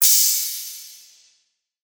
SOUTHSIDE_open_hihat_2k.wav